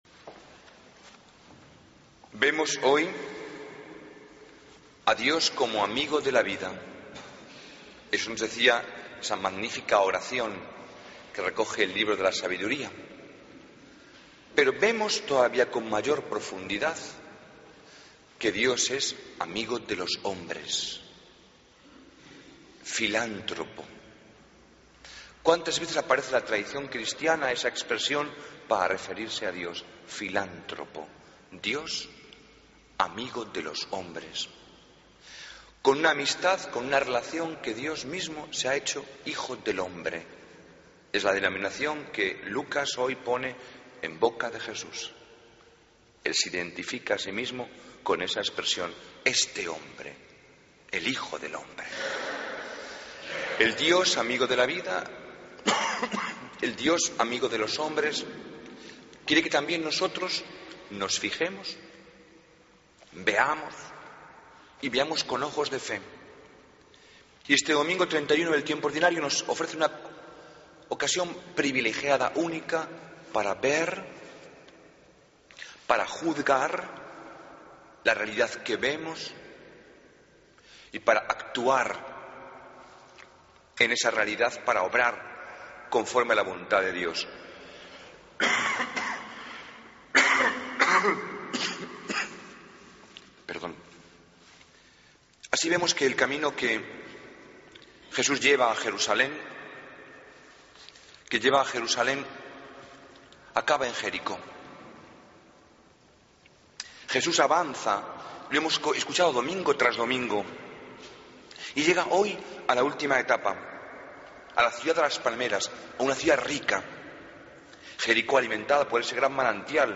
Homilía del Domingo 3 de Noviembre de 2013